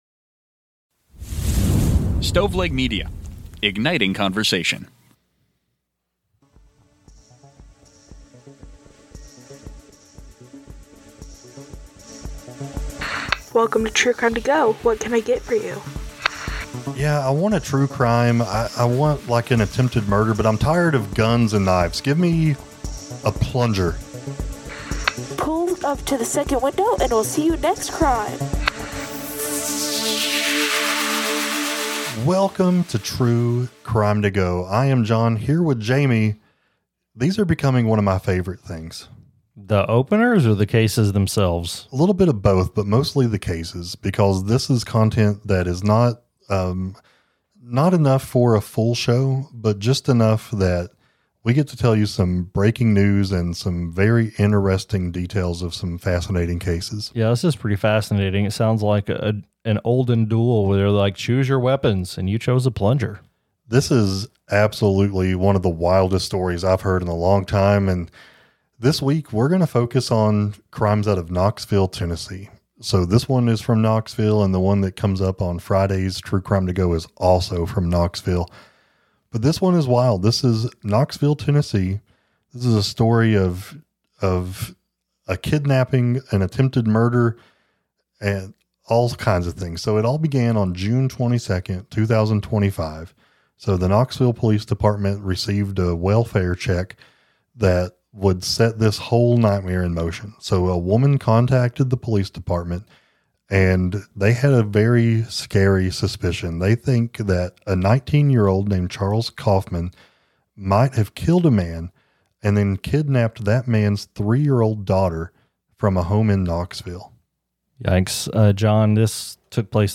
Documentary, Personal Journals, True Crime, Society & Culture